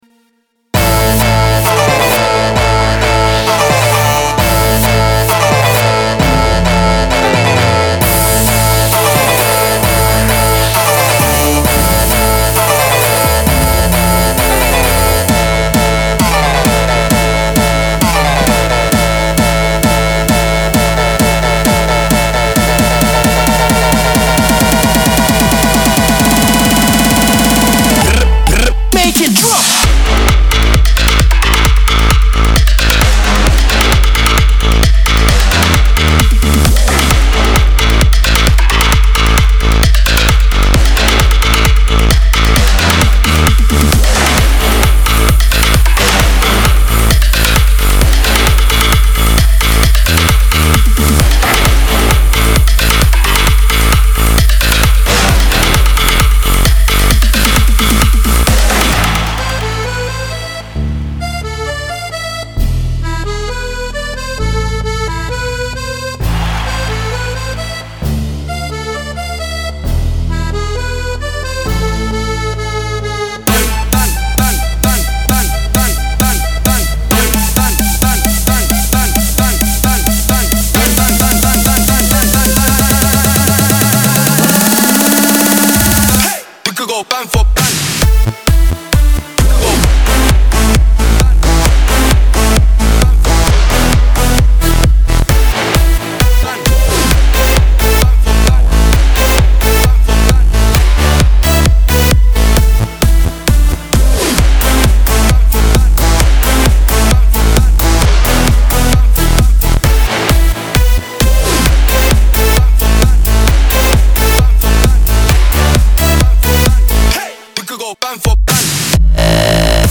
יש שם אוסף סגנונות אז זה קצת מעורבב…